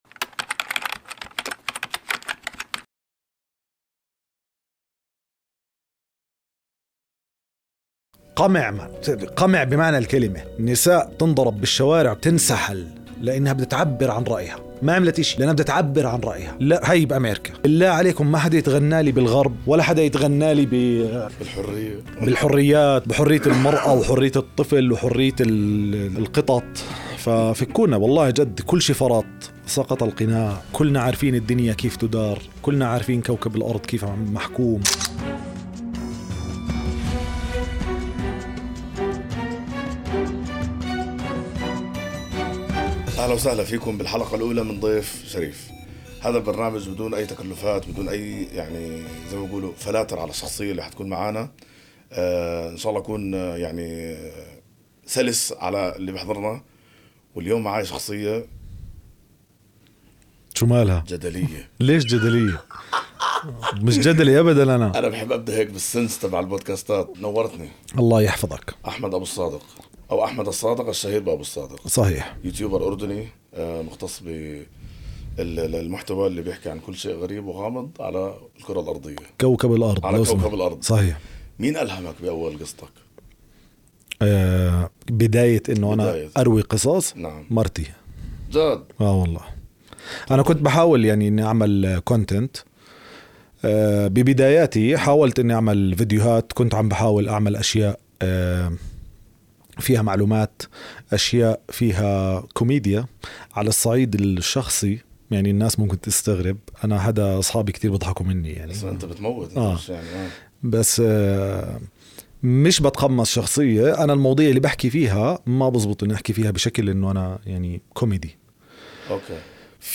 بودكاست ضيف شريف | حوار صادق